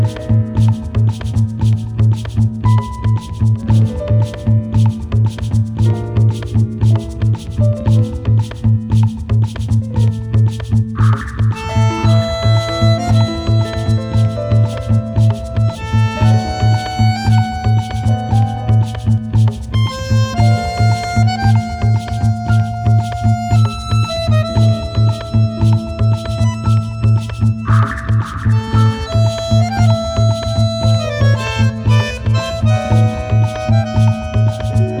Жанр: Танцевальные / Электроника / Хаус